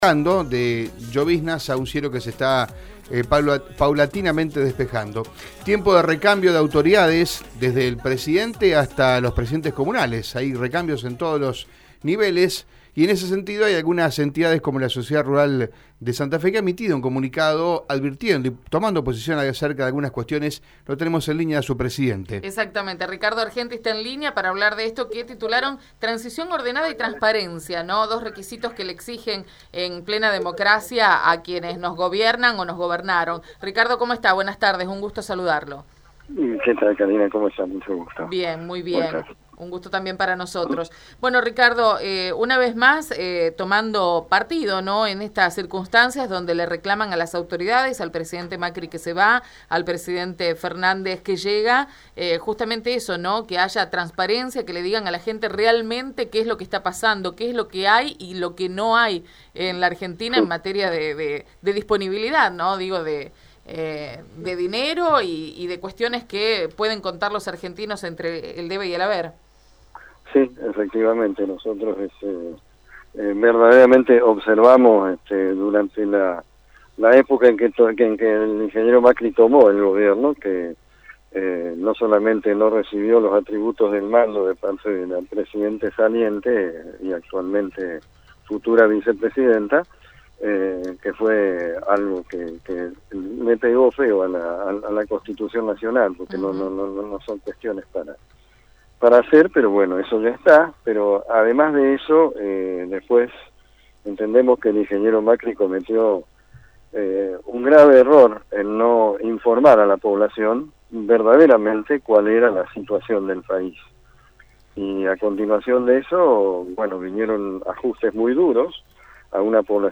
En diálogo con Radio EME